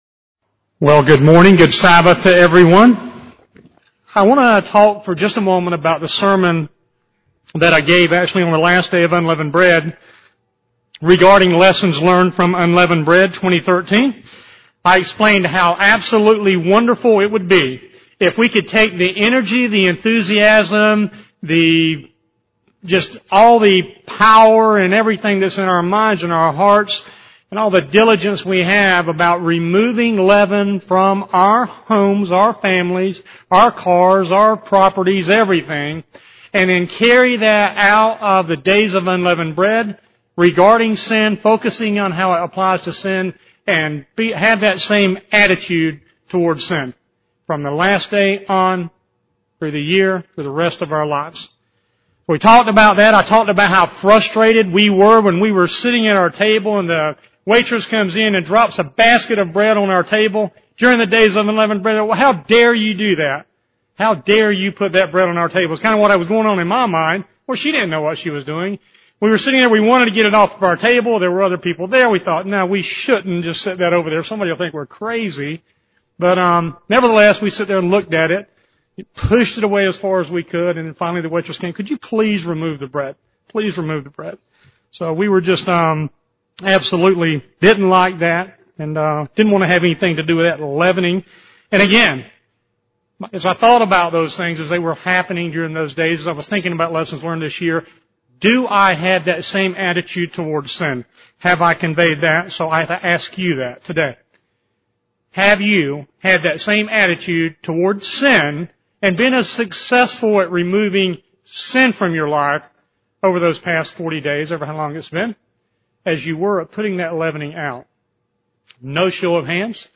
Given in Raleigh, NC
Print "The Gift of Forgiveness" one of the great keys to getting along and acheiving reconciliation is learning how to forgive and putting it into practice UCG Sermon Studying the bible?